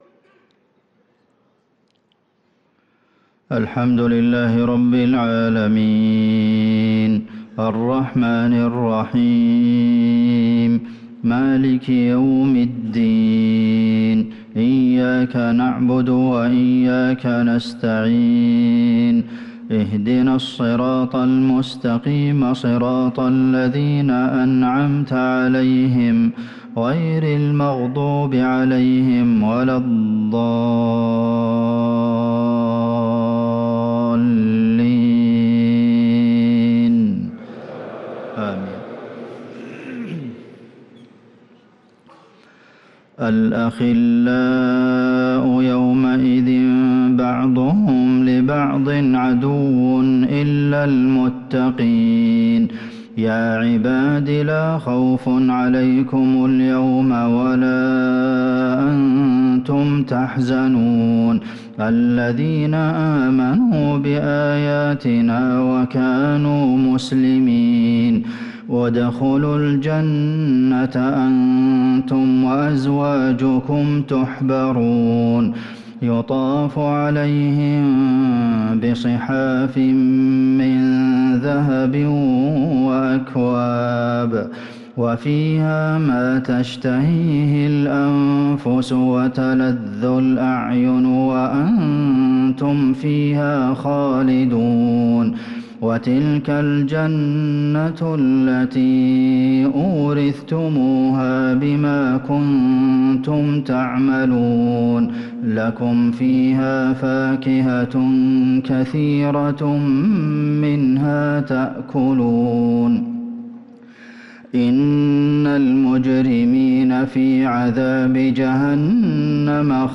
صلاة العشاء للقارئ عبدالمحسن القاسم 18 ربيع الآخر 1445 هـ
تِلَاوَات الْحَرَمَيْن .